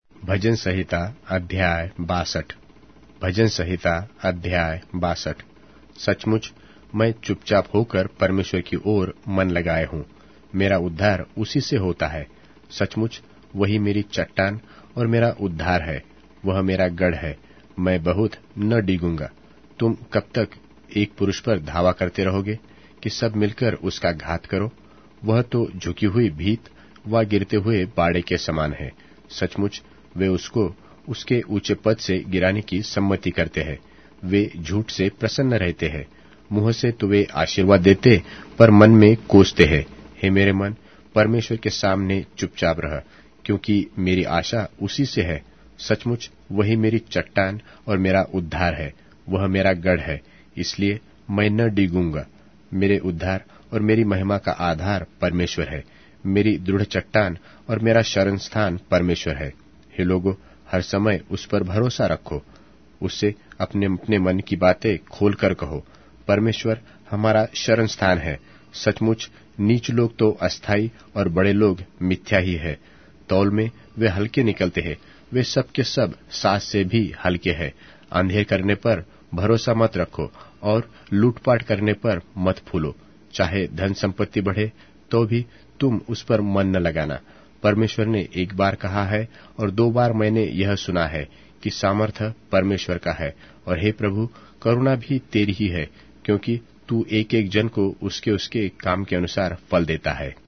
Hindi Audio Bible - Psalms 69 in Nlt bible version